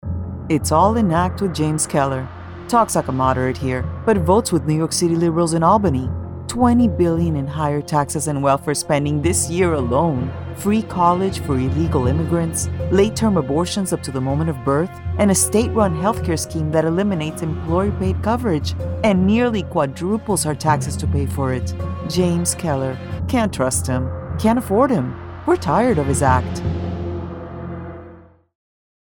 Female Spanish Republican Political Voiceover
English, SP accent